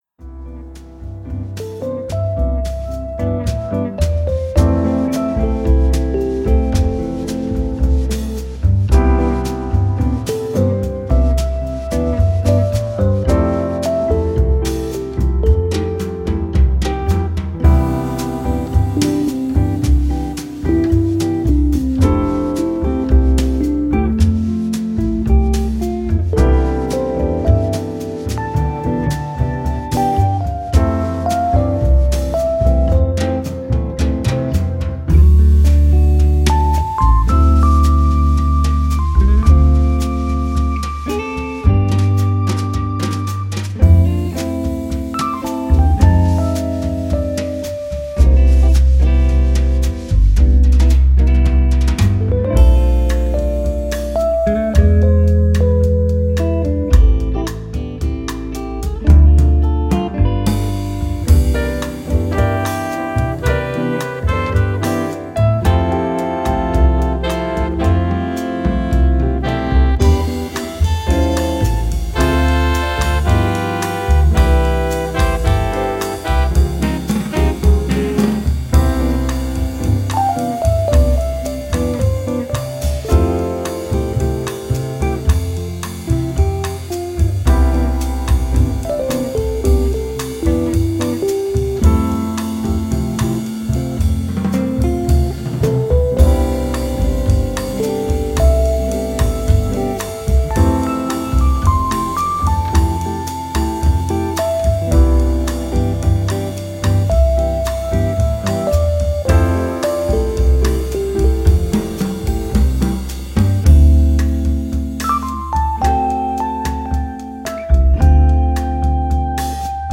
Here is the Quarantine Bossa Nova (or QBossa for short):